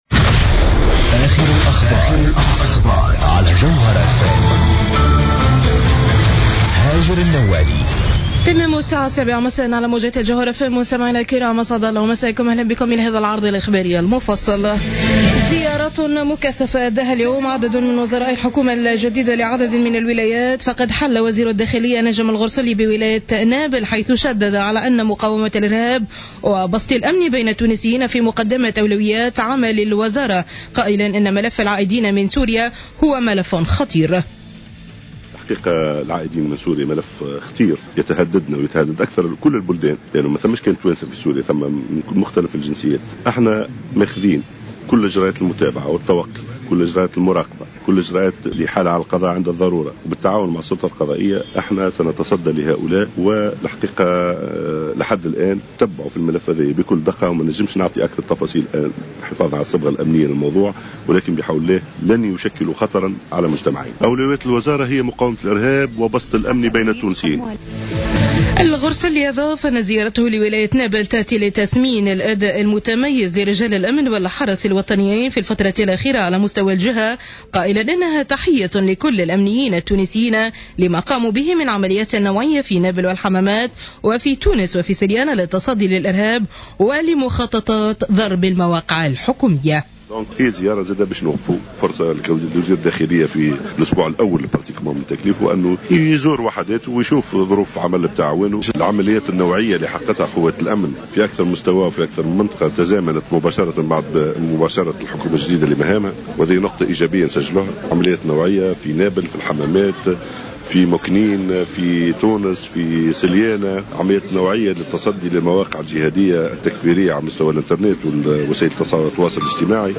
نشرة أخبار السابعة مساء ليوم السبت 14 فيفري 2015